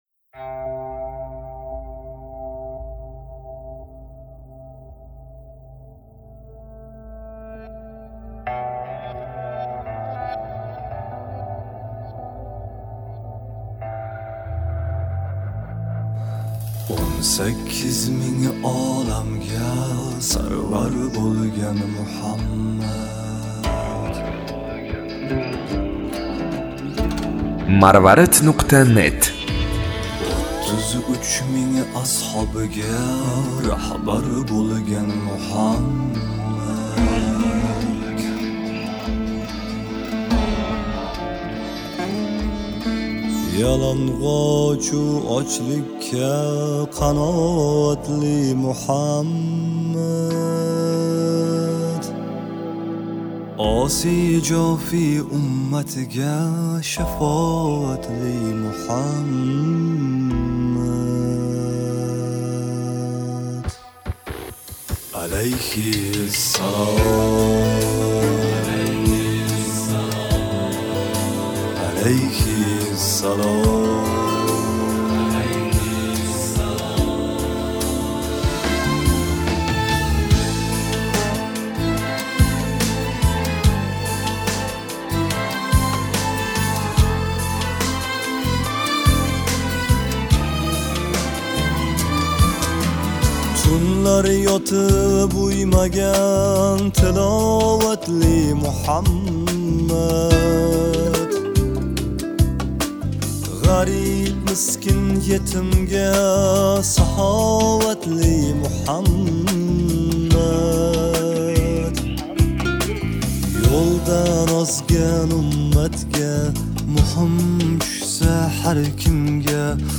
Аллоҳнинг Расули ҳақида жозибали қӯшиқ...